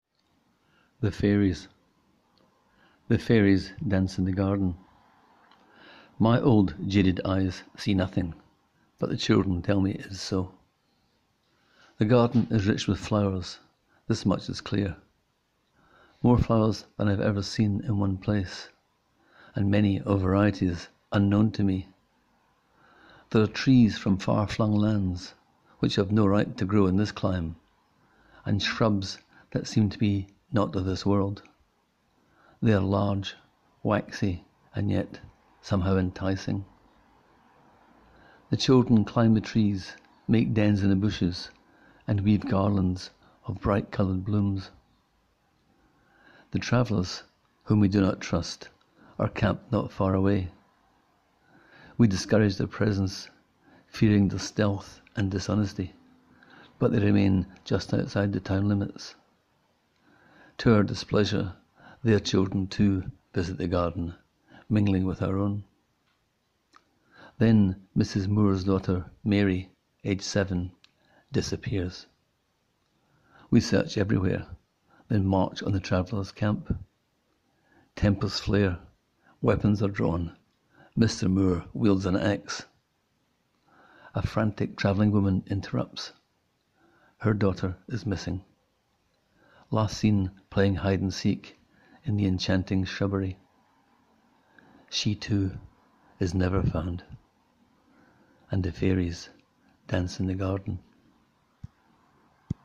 Click here to hear the author read the tale: